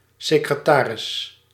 Ääntäminen
IPA : /ˈsɛk.ɹəˌtə.ɹi/ IPA : /ˈsɛk.ɹə.tɹi/ US : IPA : /ˈsɛkɹətɛɹi/